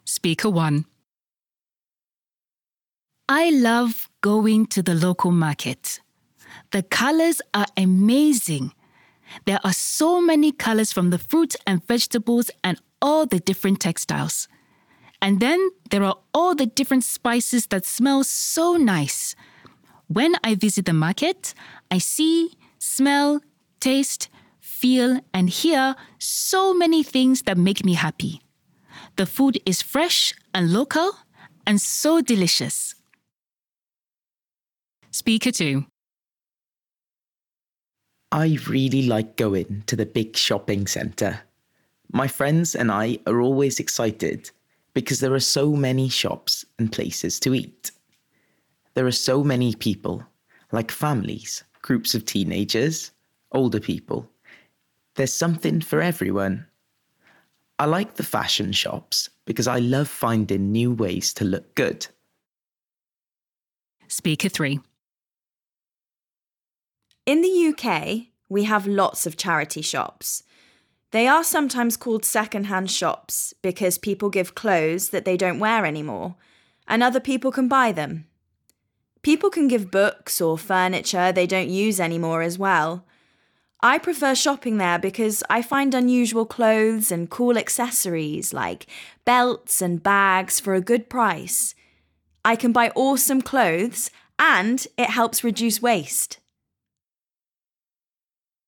• Tell the learners that they're going to listen to three people talking about these different places to shop.
A1_Monologue1_Where_do_you_shop_v1.mp3